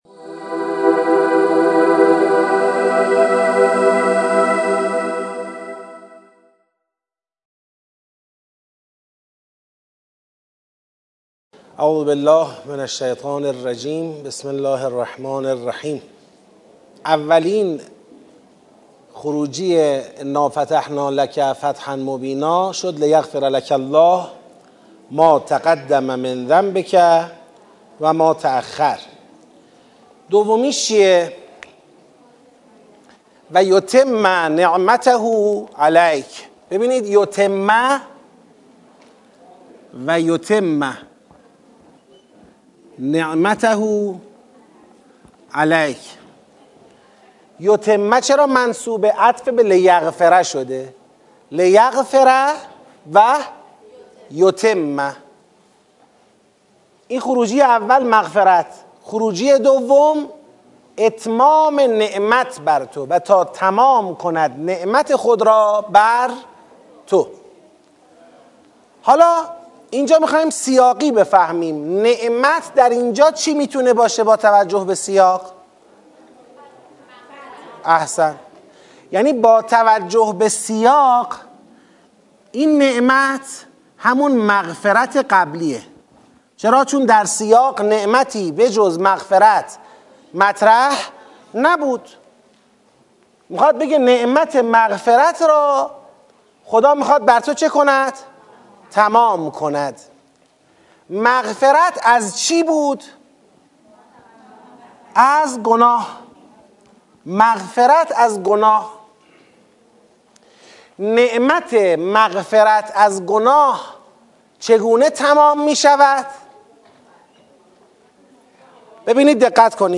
ترم ششم این دوره از مهر ۱۴۰۱ در سالن شهید آوینی مسجد حضرت ولیعصر (عج) شهرک شهید محلاتی آغاز شد. در فایل دوازدهم سطح شش (هفته ششم)، بخش دوم نظام سوره فتح تدریس می شود.